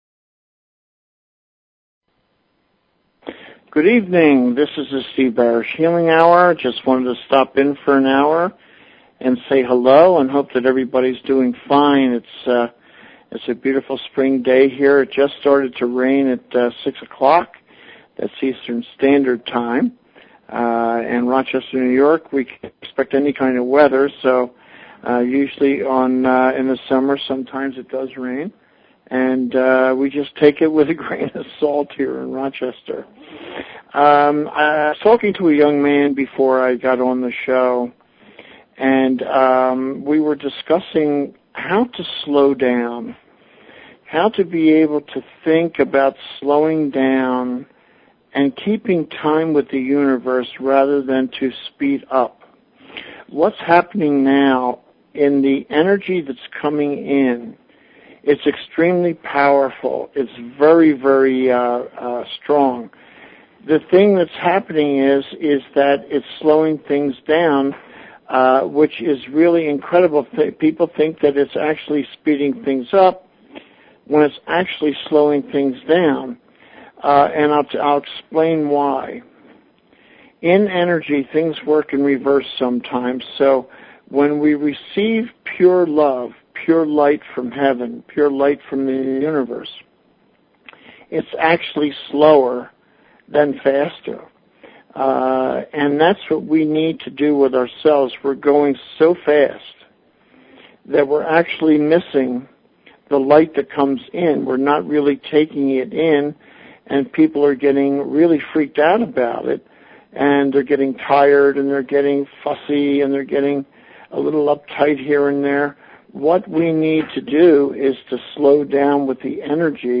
Talk Show Episode, Audio Podcast, The_Healing_Hour and Courtesy of BBS Radio on , show guests , about , categorized as